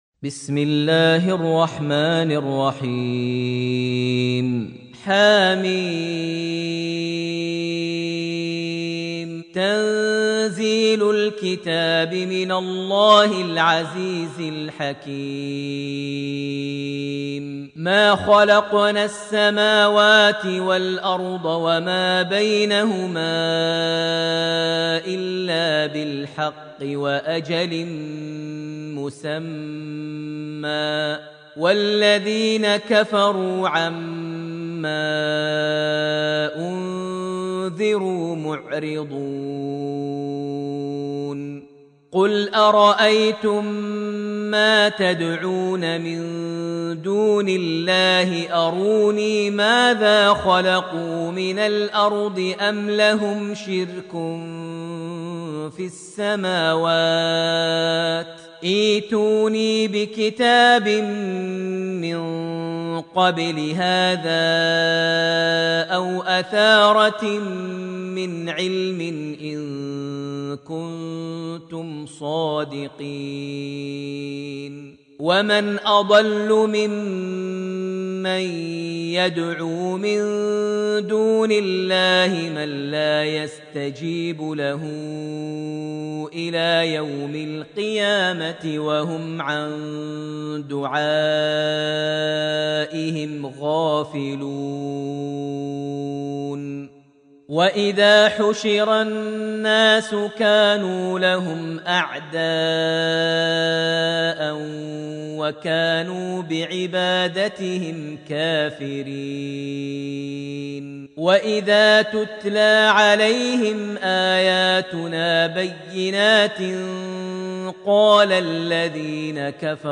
surat Al-Ahqaf > Almushaf > Mushaf - Maher Almuaiqly Recitations